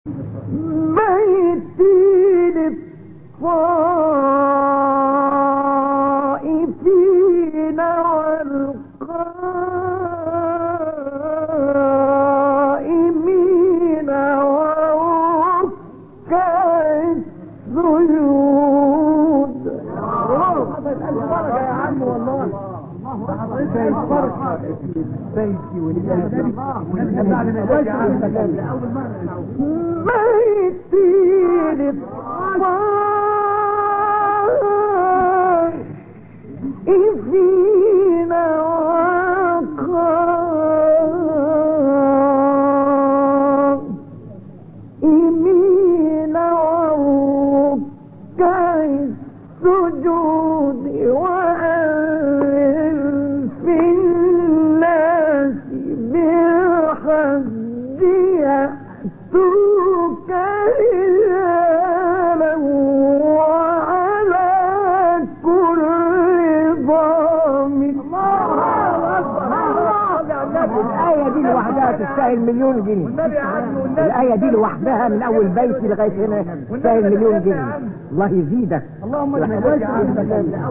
مقطع میلیونی مصطفی اسماعیل/این مقطع زیبا و جالب مربوط به یکی از تلاوت‌های ماندگار مصطفی اسماعیل در شهر اسکندریه است؛ مصطفی اسماعیل مشغول تلاوت سوره مبارکه حج هستند و آیه 25 و 26 این سوره رو میخونن: وإِذْ بَوَّأْنَا لِإِبْرَاهِیمَ مَکَانَ الْبَیْتِ أَن لَّا تُشْرِکْ بِی شَیْئًا وَطَهِّرْ بَیْتِیَ لِلطَّائِفِینَ وَالْقَائِمِینَ وَالرُّکَّعِ السُّجُودِ وَأَذِّن فِی النَّاسِ بِالْحَجِّ یَأْتُوکَ رِجَالًا وَعَلَى کُلِّ ضَامِرٍ یَأْتِینَ مِن کُلِّ فَجٍّ عَمِیقٍ (و چون براى ابراهیم جاى خانه را معین کردیم (به او گفتیم) چیزى را با من شریک مگردان، و خانه‌ام را براى طواف کنندگان و قیام کنندگان و رکوع کنندگان و سجده کنندگان پاکیزه دار، و در میان مردم براى اداى حج بانگ برآور تا زایران پیاده و سوار بر هر شتر لاغرى که از هر راه دورى مى‌آیند به سوى تو روى آورند.)
برچسب ها: خبرگزاری قرآن ، ایکنا ، فعالیتهای قرآنی ، مقاطع صوتی ، فراز صوتی ، تلاوت ، راغب مصطفی غلوش ، شحات محمد انور ، محمد الفیومی ، محمود شحات انور ، محمد عبدالعزیز حصان ، متولی عبدالعال ، مقطع میلیونی مصطفی اسماعیل ، قرآن